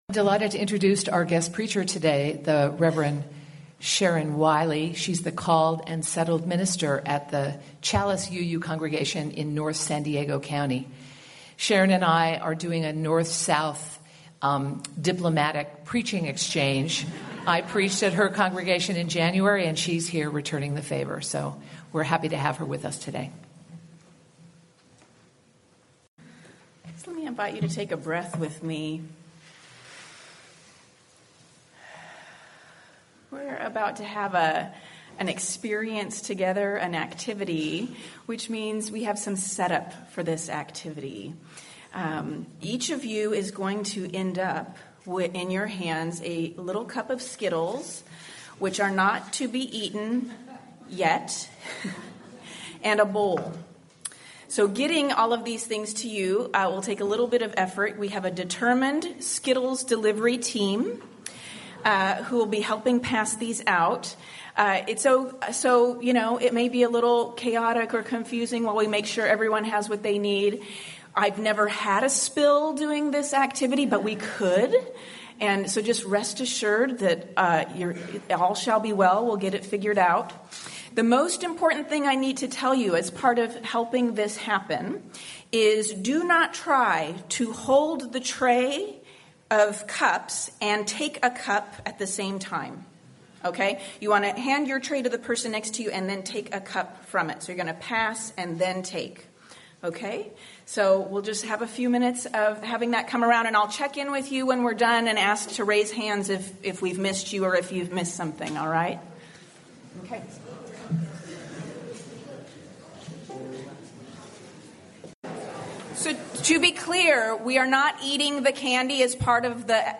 Sermon-Wrestling-with-Privilege.mp3